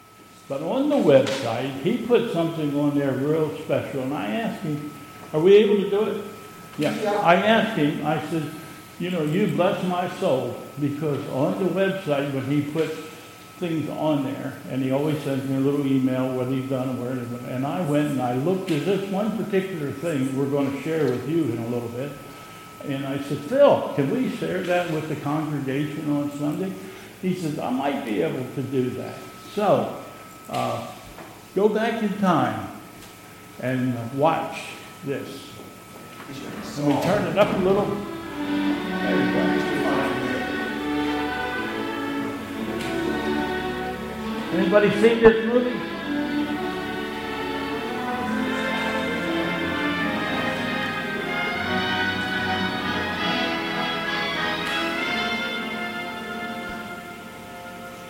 2022 Bethel Covid Time Service
Independence Sunday
Announcements and anniversaries...